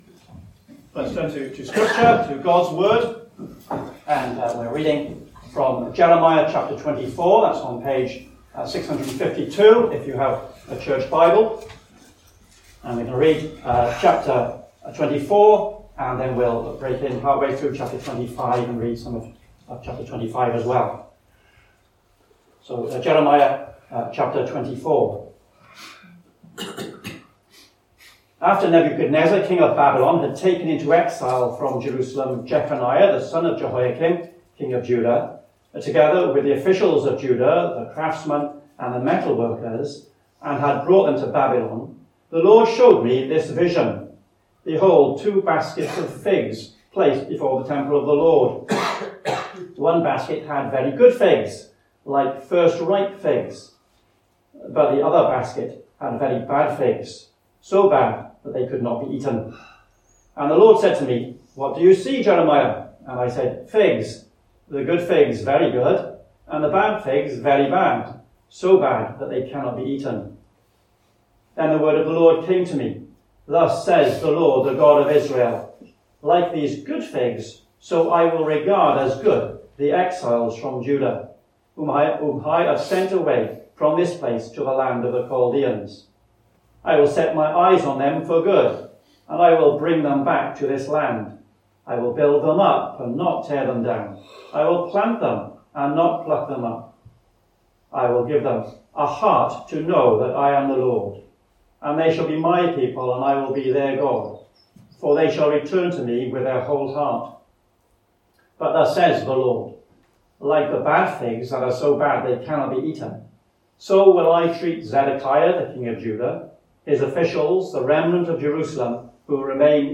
A link to the video recording of the 6:00pm service, and an audio recording of the sermon.